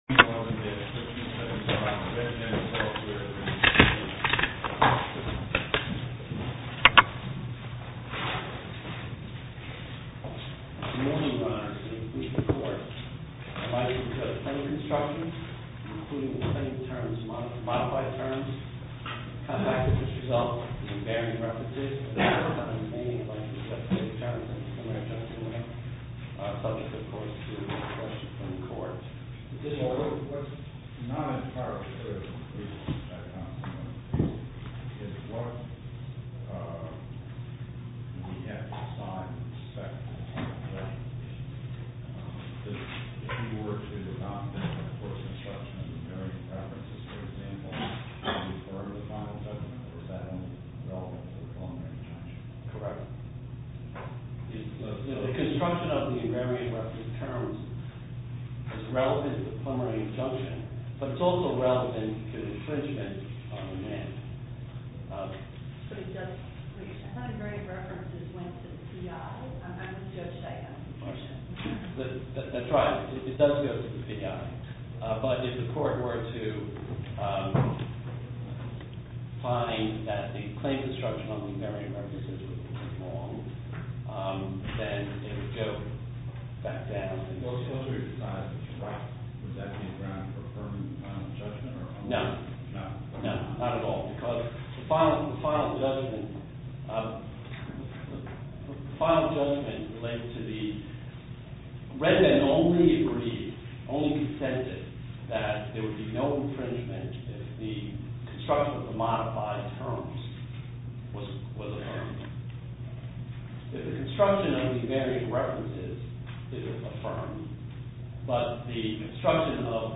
To listen to more oral argument recordings, follow this link: Listen To Oral Arguments.